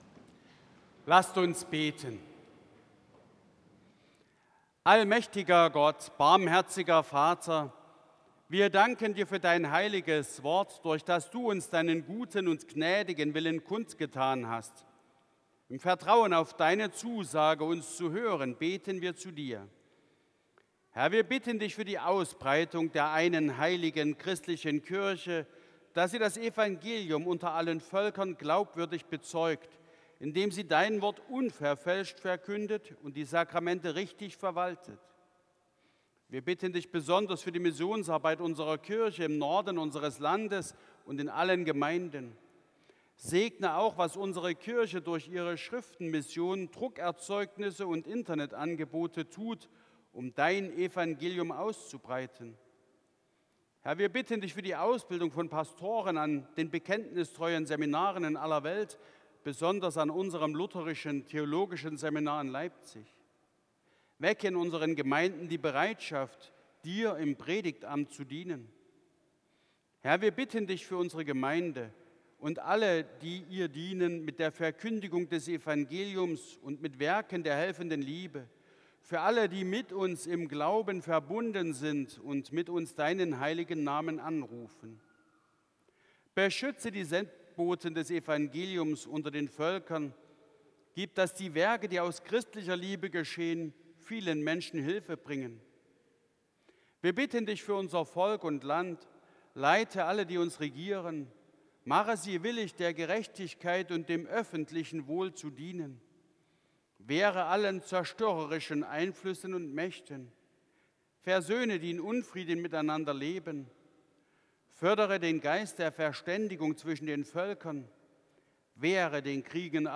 11. Gebet, Vaterunser, Entlassung und Segen Evangelisch-Lutherische St. Johannesgemeinde Zwickau-Planitz
Audiomitschnitt unseres Gottesdienstes vom Letzten Sonntag nach Epipanias 2026.